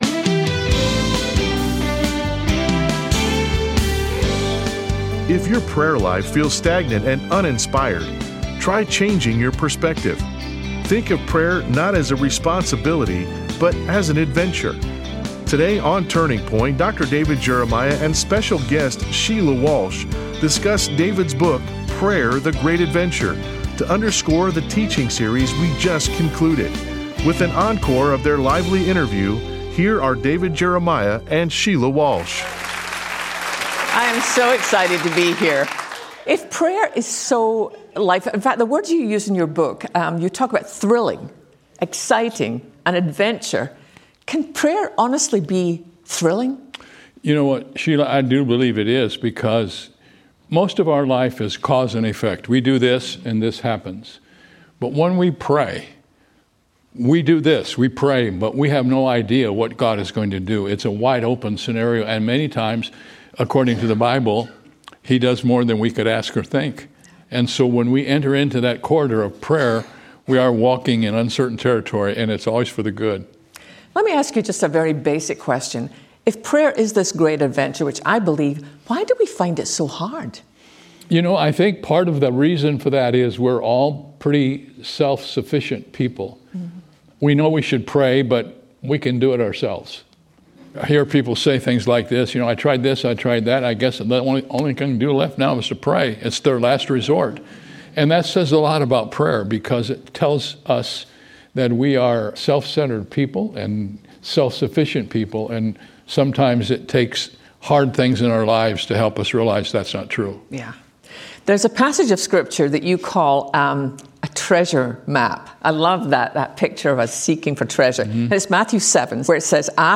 Prayer: The Great Adventure Interview with Dr. David Jeremiah
Dr. Jeremiah and Sheila Walsh talk about how Prayer has the potential to be life changing in this special interview.